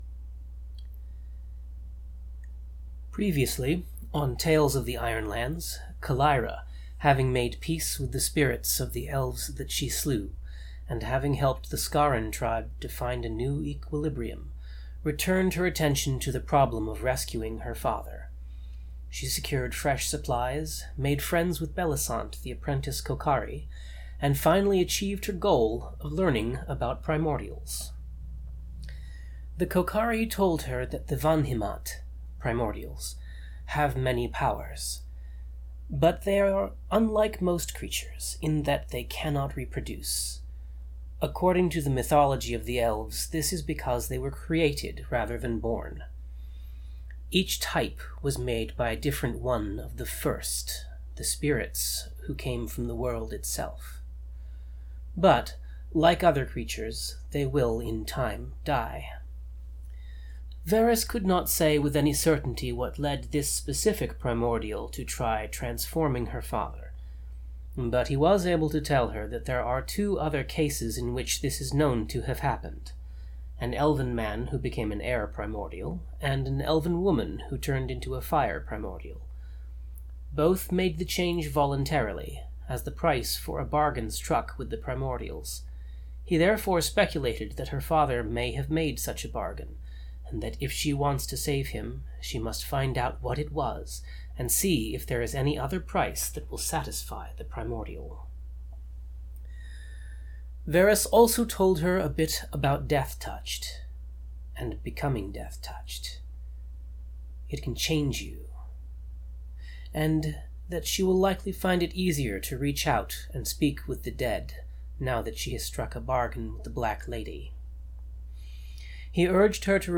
Note: This has been edited to remove uninteresting bits such as silence, throat-clearing and paper shuffling.